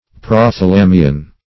Prothalamion \Pro`tha*la"mi*on\, Prothalamium